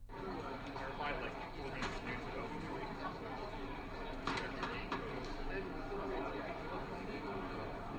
ハイブリッド型アクティブノイズキャンセリング
また、環境認識モードで反対に、周囲の音を聞くこともできるので、かなり高性能だと思います。
razer-blackshark-v3-pro-hybrid-anc-ambient.wav